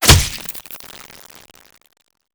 combat / weapons / generic rifle
flesh1.wav